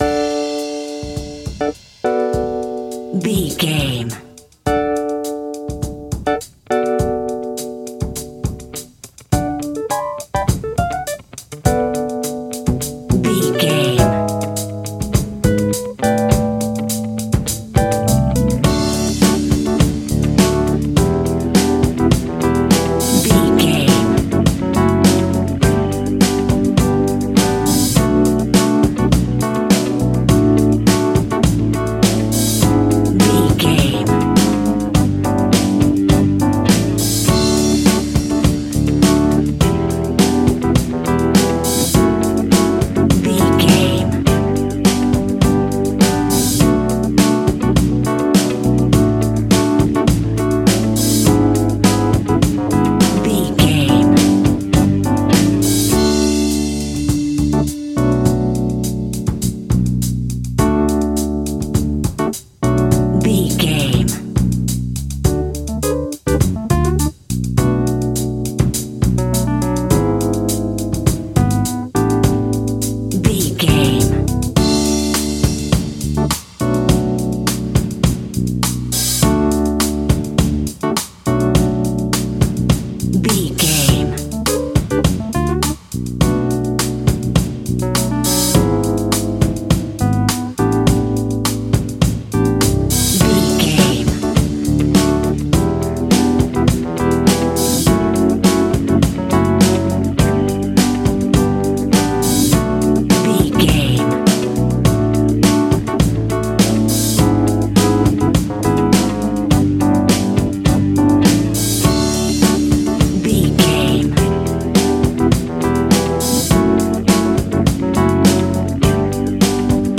Ionian/Major
D
funky
uplifting
bass guitar
electric guitar
organ
drums
saxophone
groovy